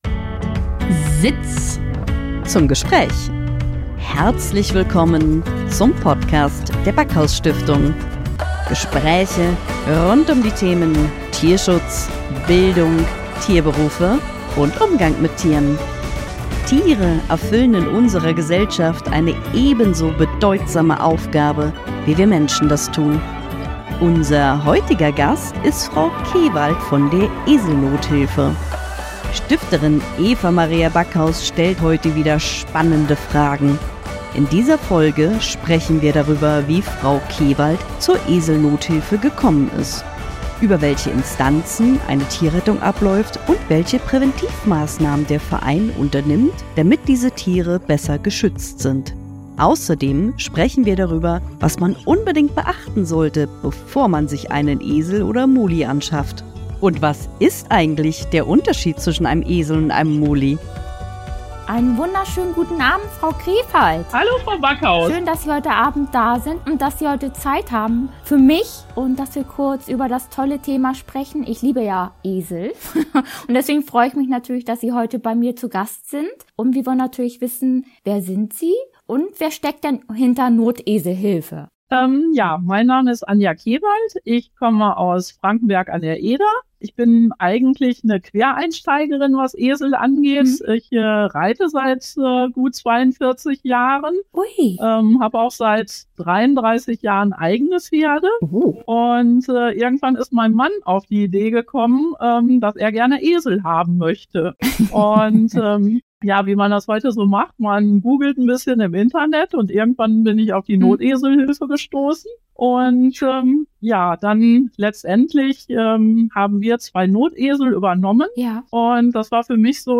In dieser Folge haben wir im Interview: